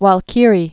(wäl-kîrē, -kīrē, väl-, wälkə-rē, väl-)